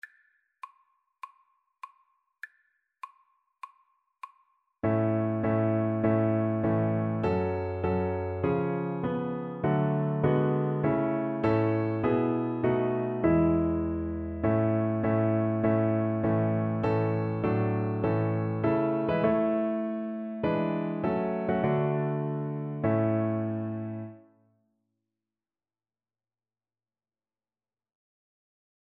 Christian Christian Cello Sheet Music Man of Sorrows - What a Name
A major (Sounding Pitch) (View more A major Music for Cello )
4/4 (View more 4/4 Music)
Classical (View more Classical Cello Music)